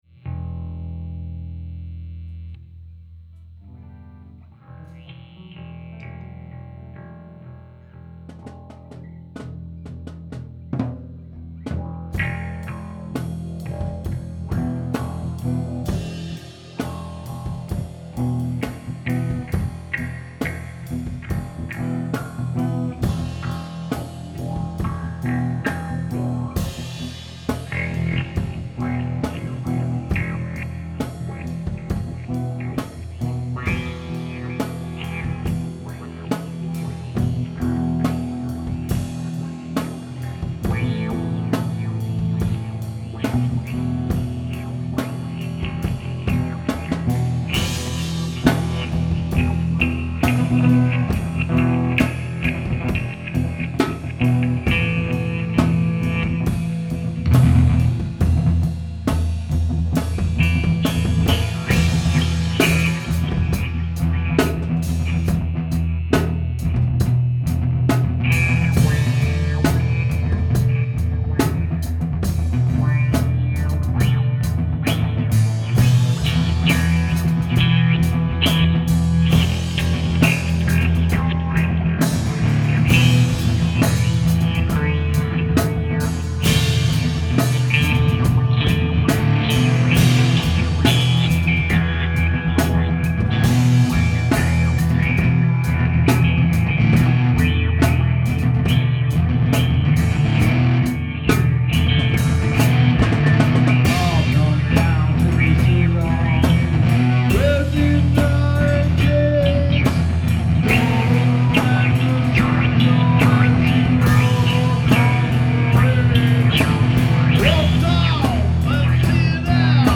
Recorded improvisation on June 7
bass, keyboards, vocals
guitar
drums